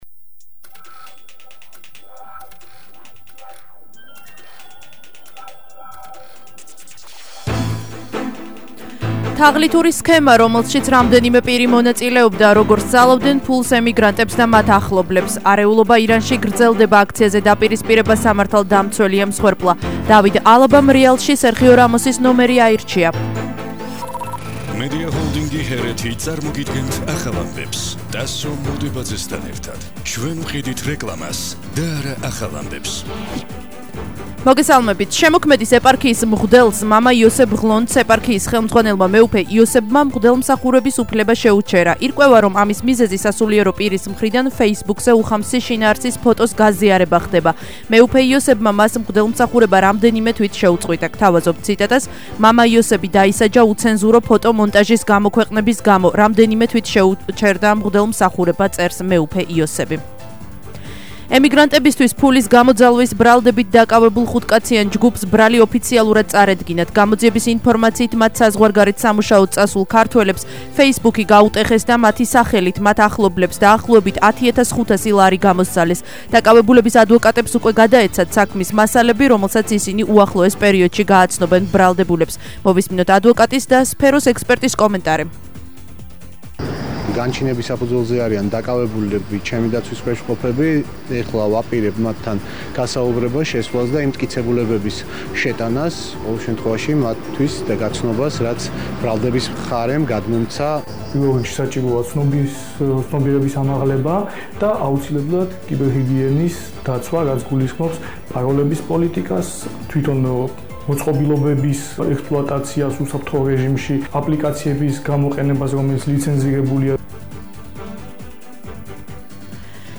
ახალი ამბები 19:00 საათზე –21/07/21